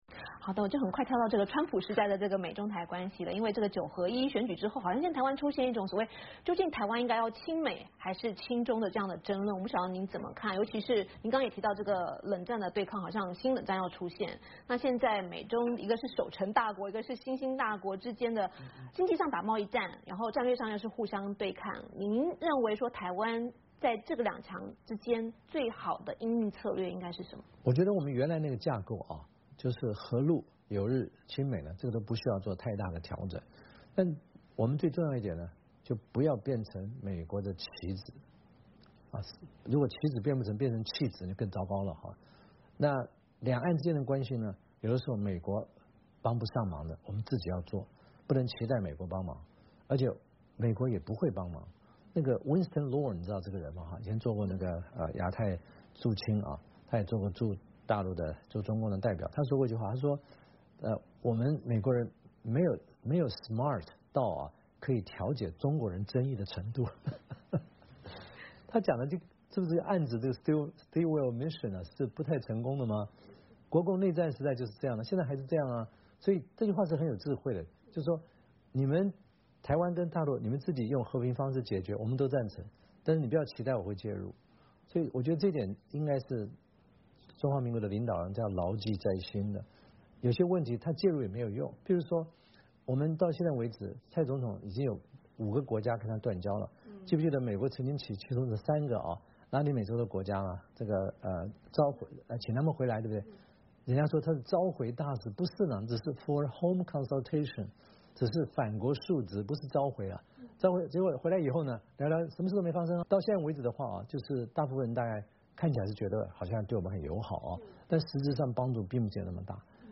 VOA专访:前台湾总统马英九谈两岸关系与习近平